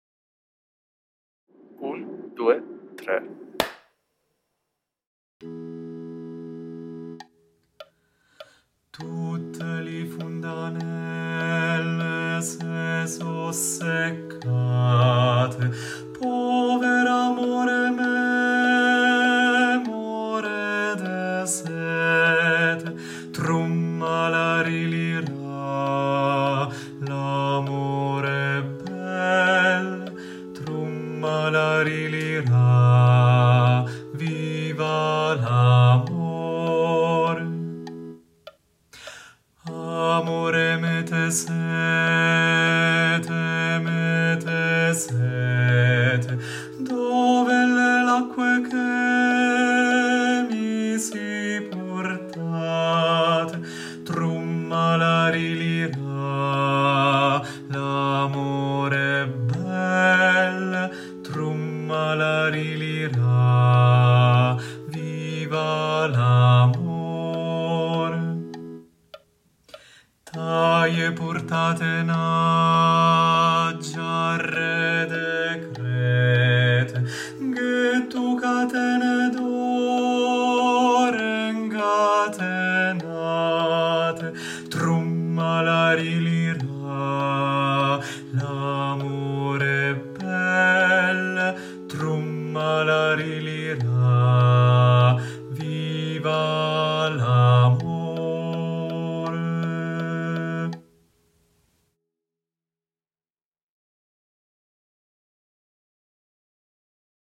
🎧 Voce guida
Bassi